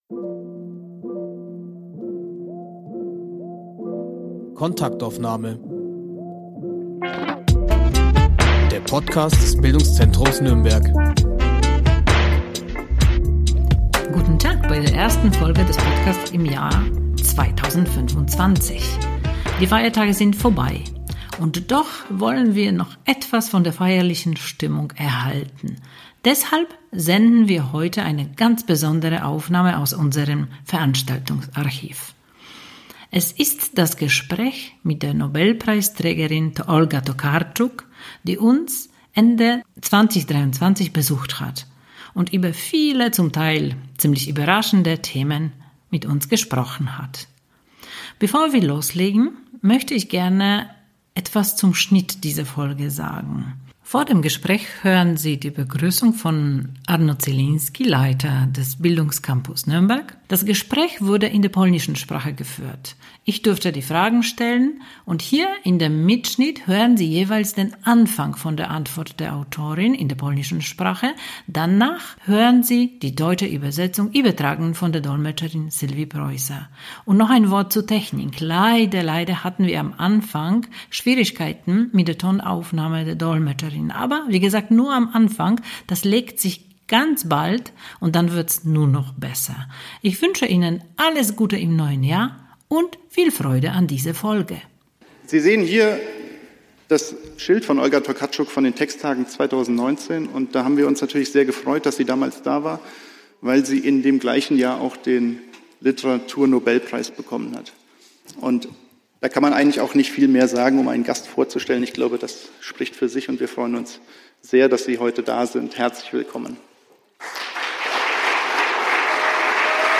In dieser Folge präsentieren wir ein besonderes Gespräch aus unserem Veranstaltungsarchiv mit der Literaturnobelpreisträgerin Olga Tokarczuk.